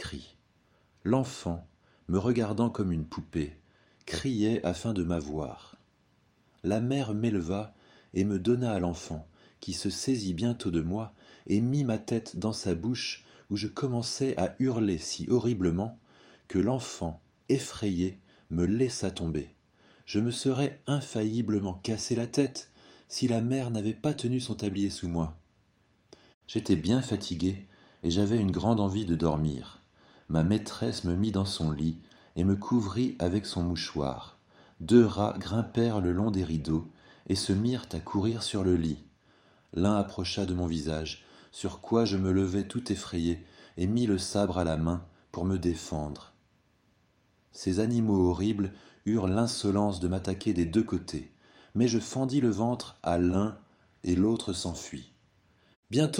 Les-voyages-de-Gulliver-Extrait-Audiobook-Contesdefees.com_-1.mp3